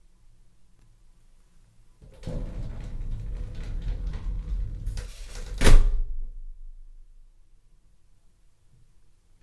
Shower Door Closes
Duration - 9 s Environment - Inside very small bathroom fully tiled, Reverb. Description - Door shuts quickly, rails, shuts.